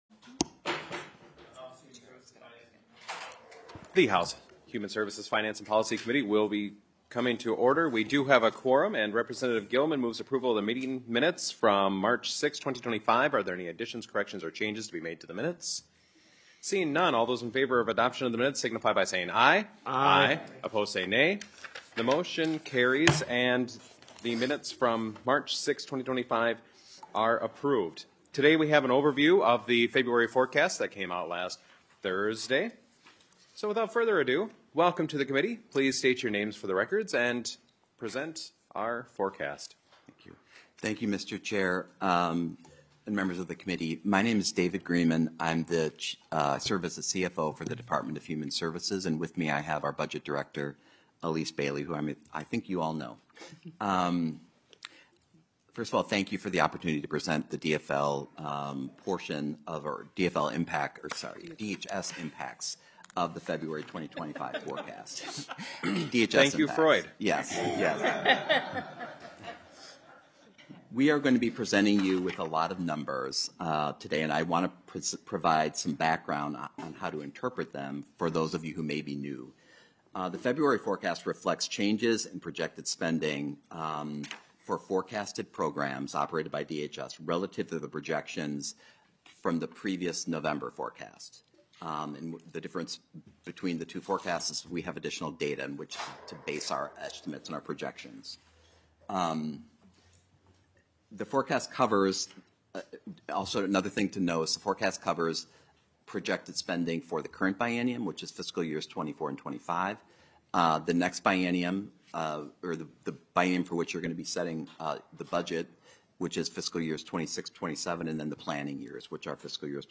Adjournment *This is an informational hearing, public testimony will not be taken